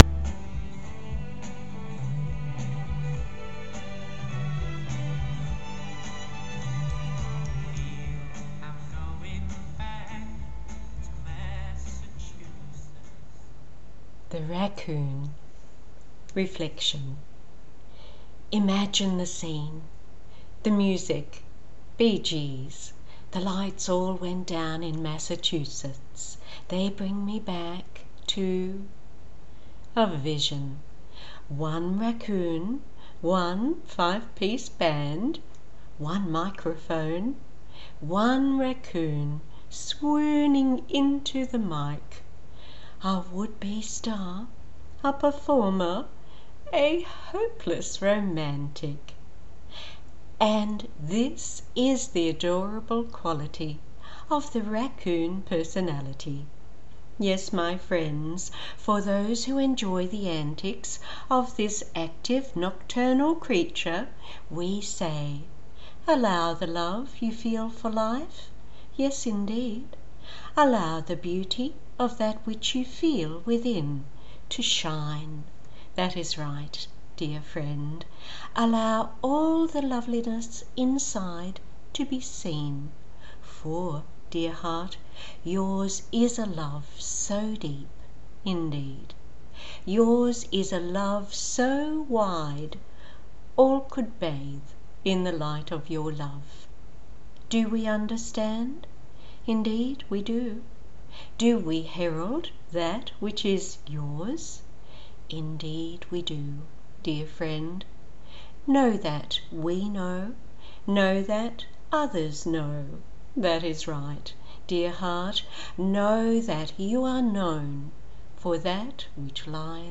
(The music) Bee Gees ~ Massachusetts…